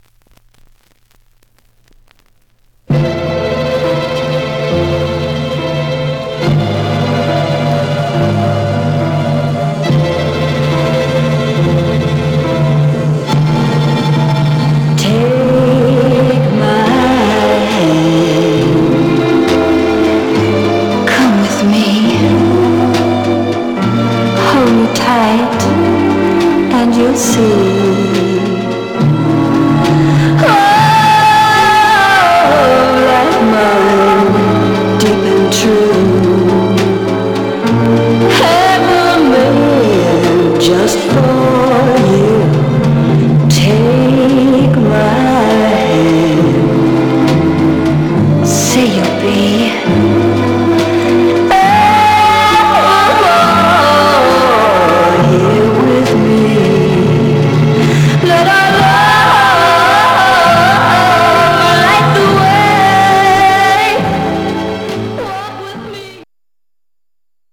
Mono
White Teen Girl Groups